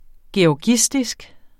Udtale [ ɕɒˈɕisdisg ] eller [ djɒːˈdjisdisg ] eller [ geɒˈgisdisg ]